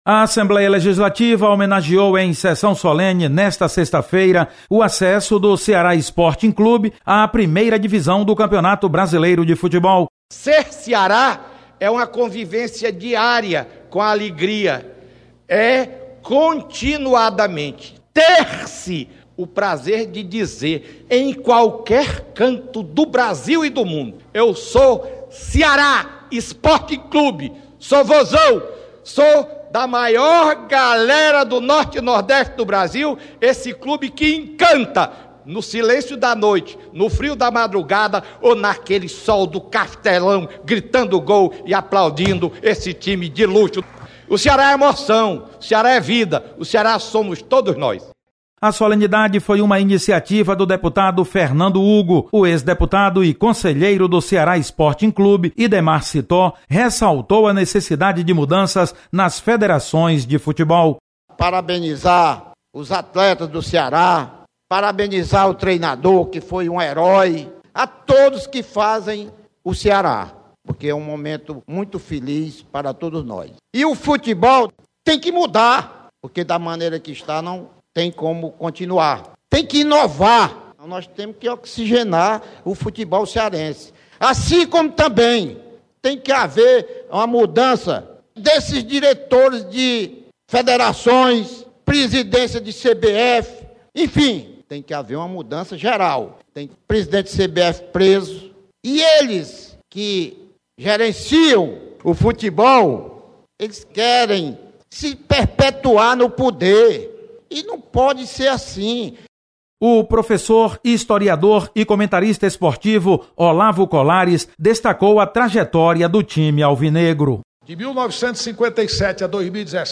Assembleia Legislativa comemora acesso do Ceará à Série A do campeonato. Repórter